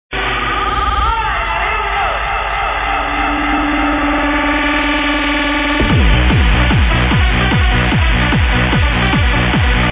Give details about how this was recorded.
Live at Club Amnesia live set